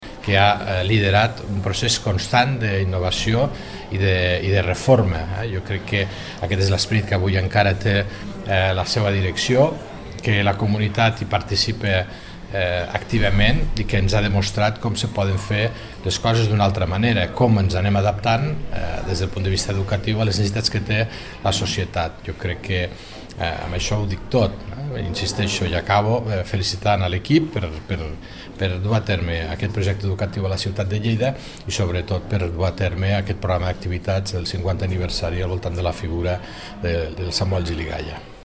Tall de veu F.Larrosa